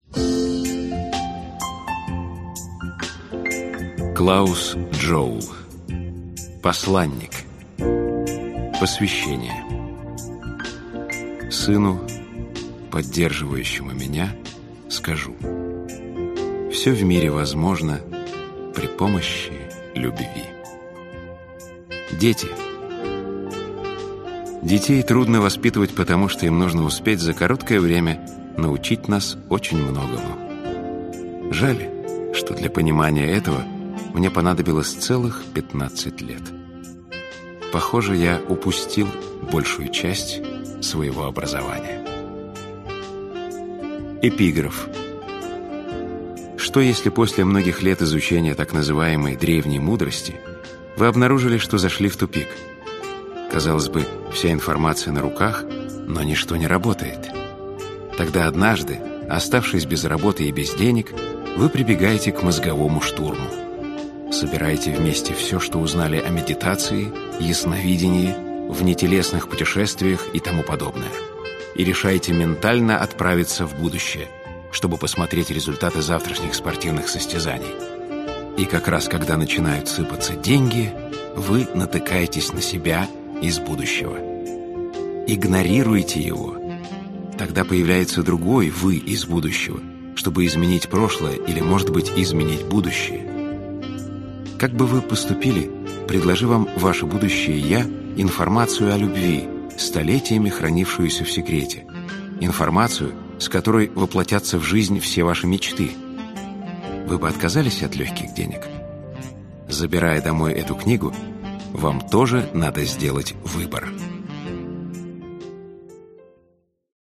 Аудиокнига Посланник. Правдивая история про любовь | Библиотека аудиокниг